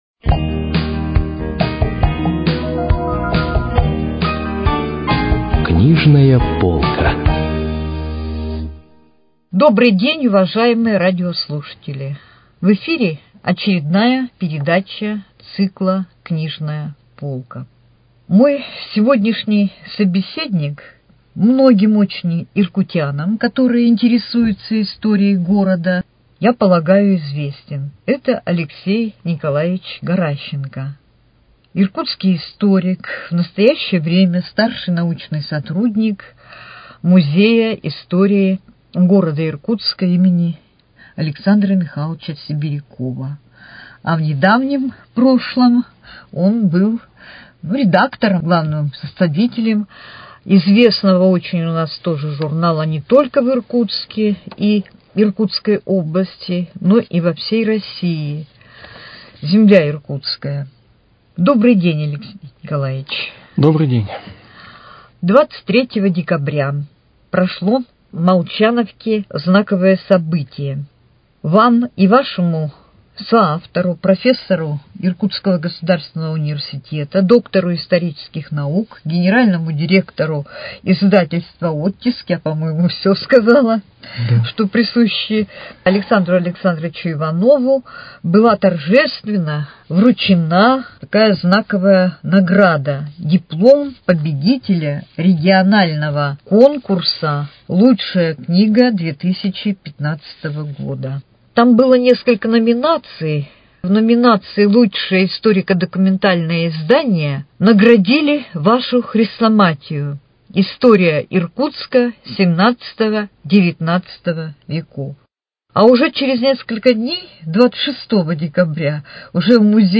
Из коллекции радиоканала - Передача из цикла «Книжная полка».